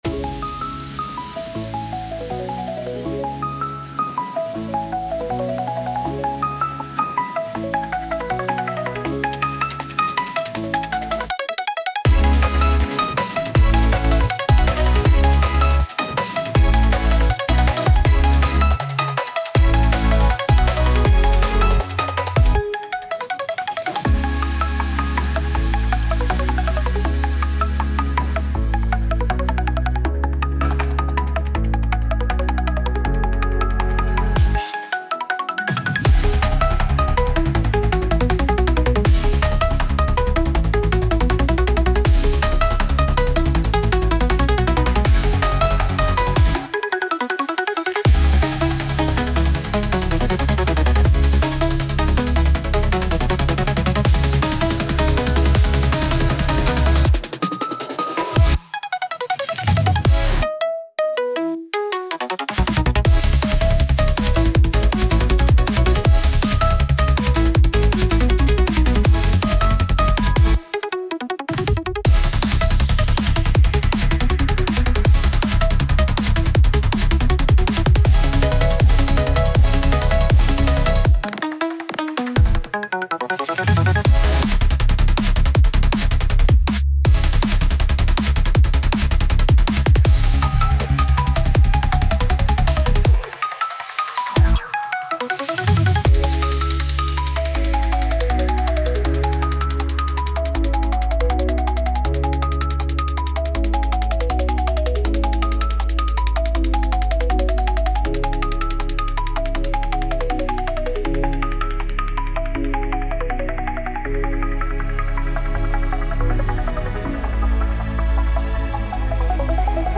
Genre: Hyperpop Mood: Energetic Editor's Choice